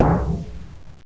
Gong
Suono di gong frequenza bassa.
TINGONG2.WAV